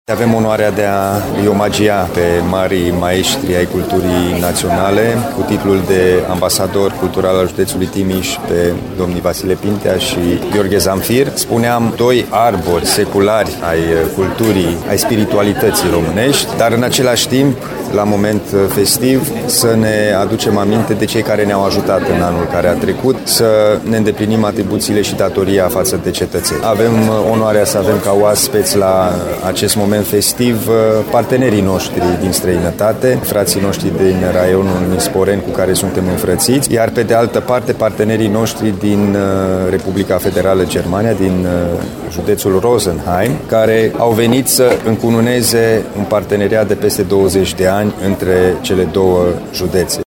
Distincțiile le-au fost înmânate de președintele Consiliului Județean, Alin Nica, în cadrului unui plen festiv de celebrare a zilei județului.
Alin-Nica-ziua-judetului.mp3